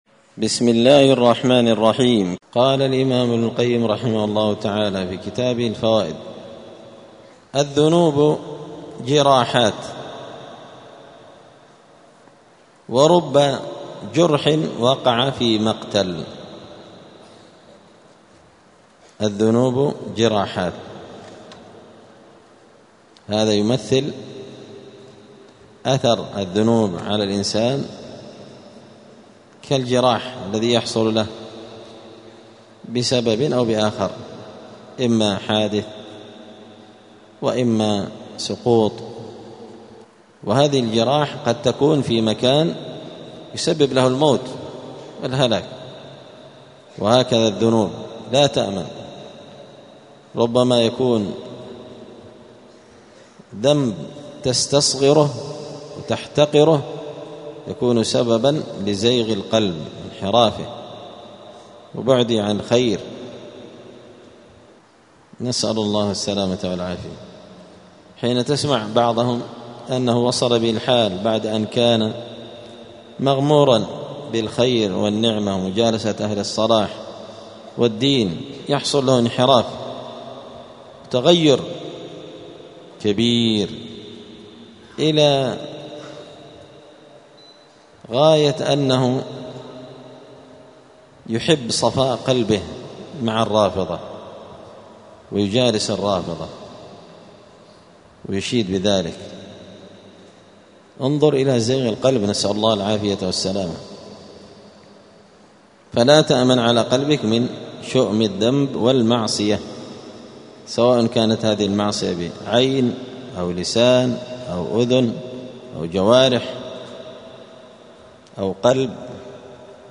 *الدرس السابع عشر (17) (فصل: الذنوب جراحات)*